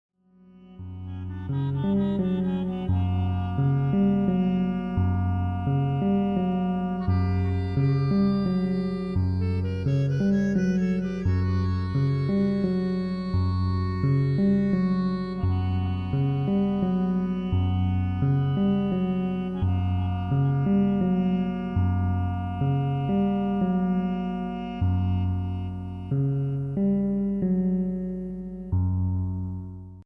F# minor